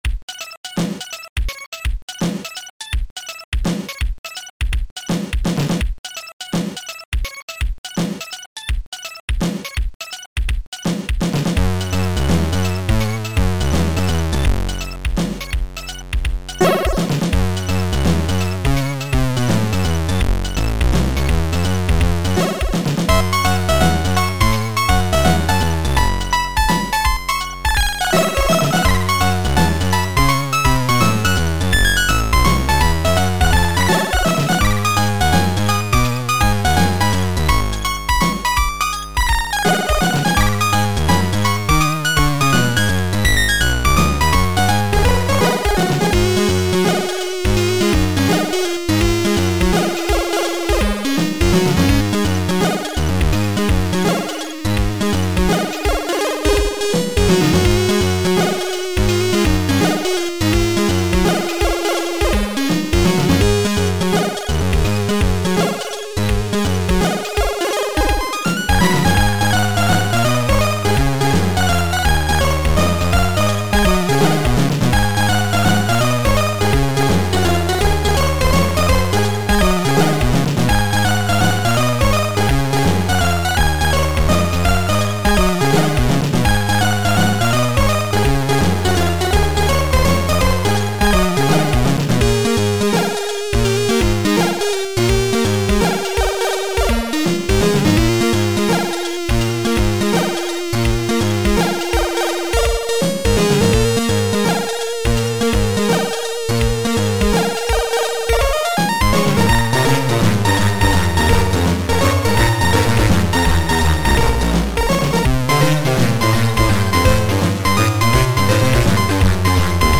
Sound Format: Soundmon 2